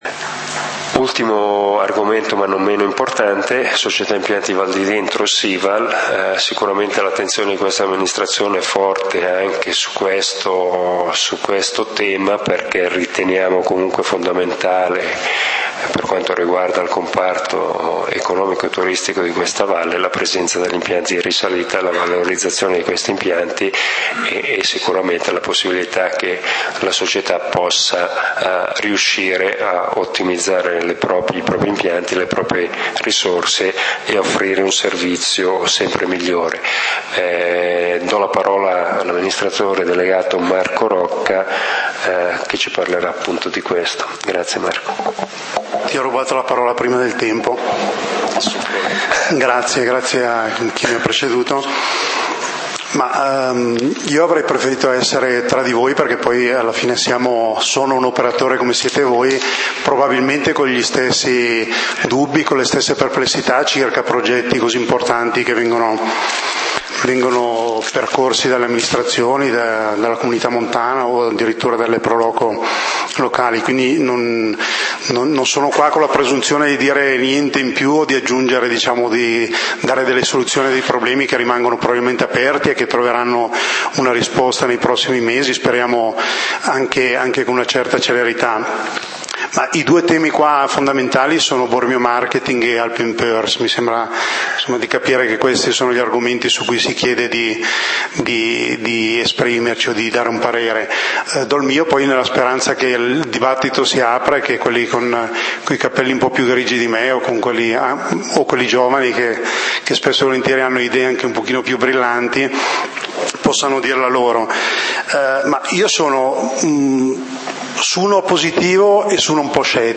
Assemblea pubblica del 14 Ottobre 2013